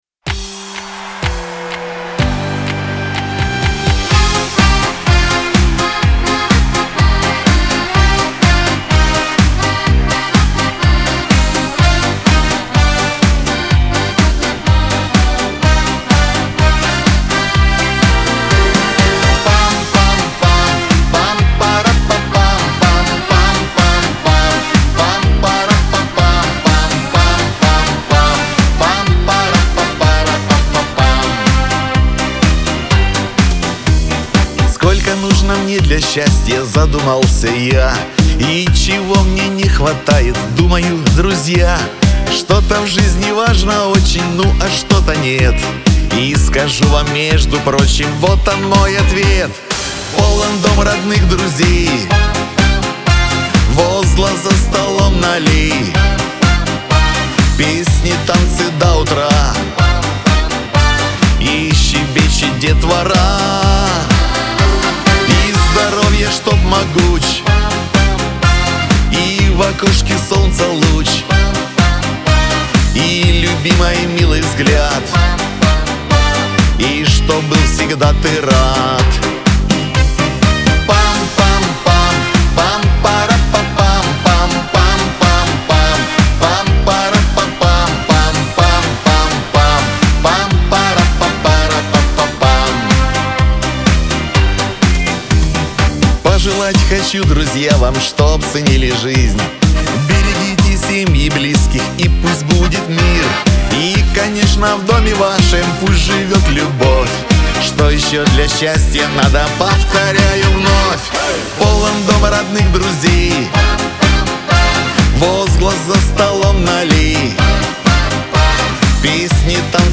Шансон
диско